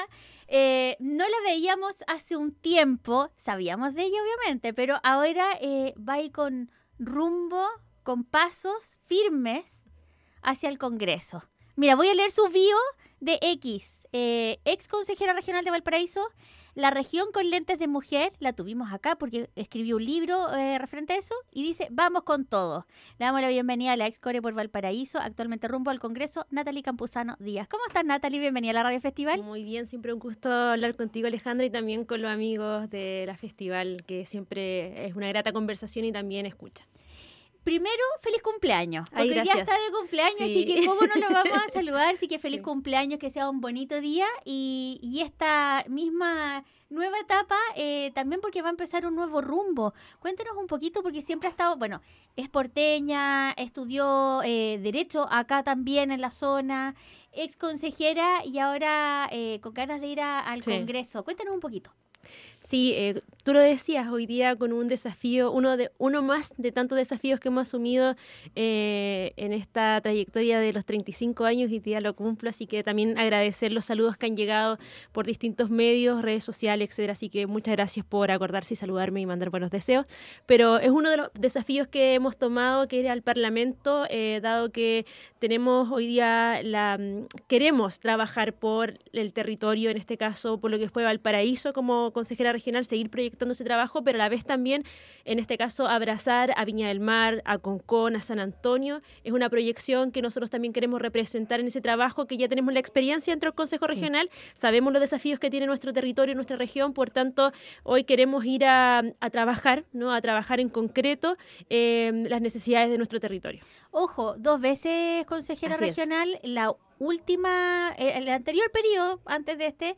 La ex Core por Valparaíso que hoy va por un cupo como Diputada por el Distrito 7 estuvo en los estudios en Colores para analizar los proyectos que quiere impulsar desde los territorios.